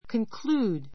conclude kənklúːd コン ク る ー ド 動詞 ❶ （話・論説などを） 締 し めくくる, 終える; 終わる ❷ 結論する, 断定する, 決める Concluded.